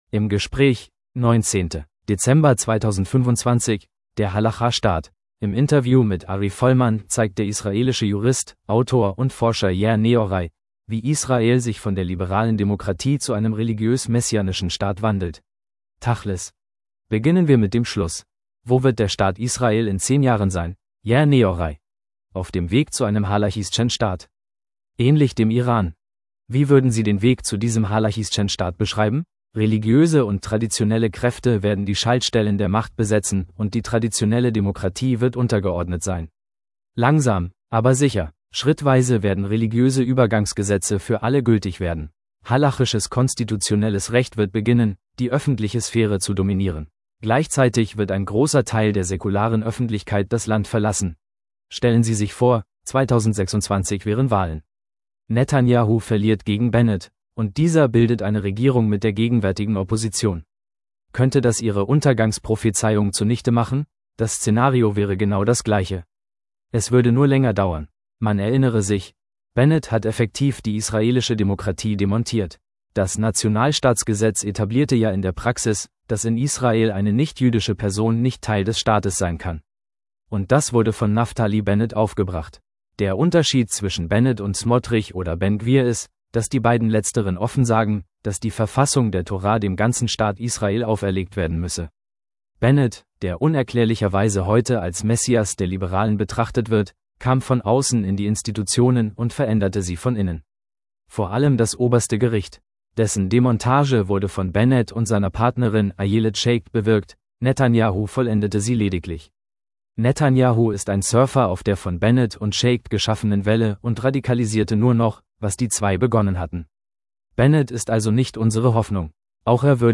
Im Interview mit Ari Folman zeigt der israelische Jurist